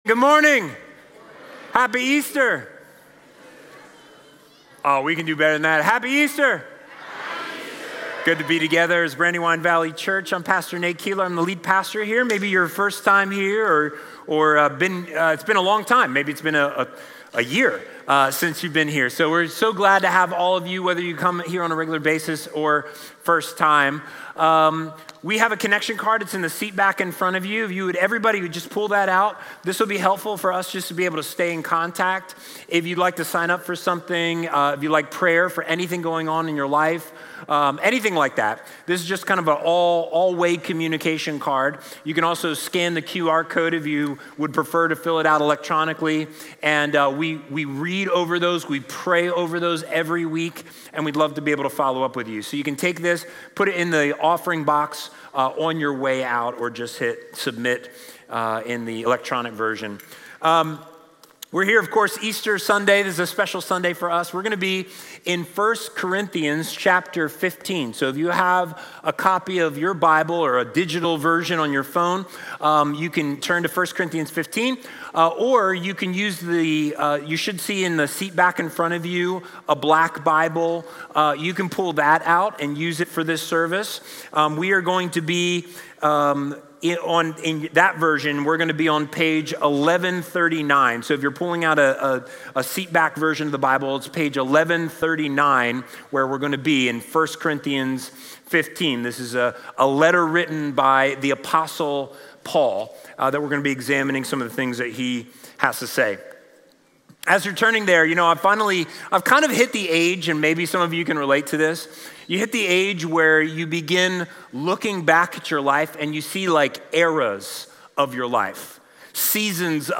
Becasue-He-Lives-Sermon.mp3